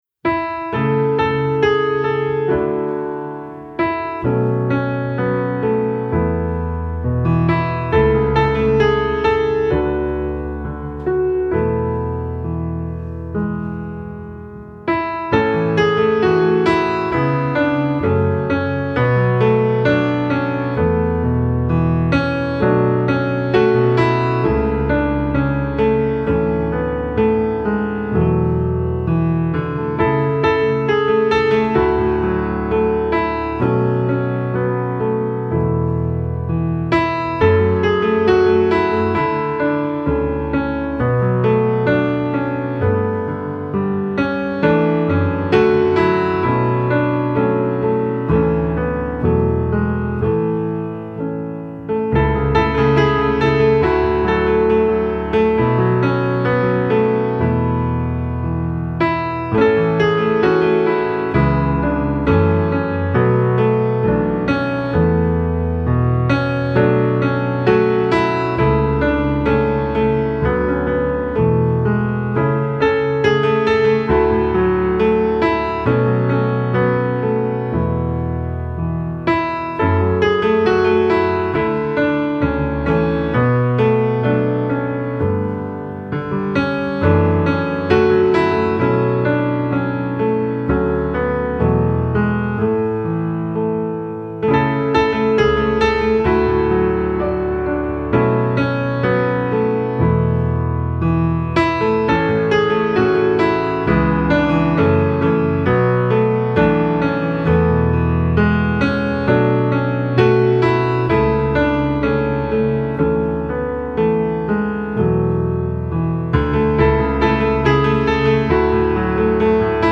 Hymn
09655-howsweetthenameofjesussounds-pianoonly.mp3